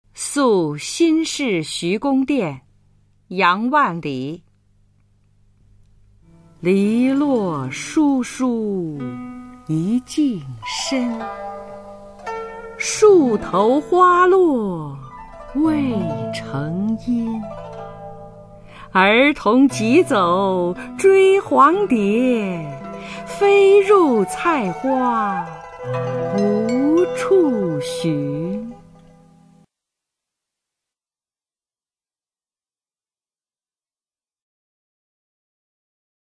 [宋代诗词诵读]杨万里-宿新市徐公店 宋词朗诵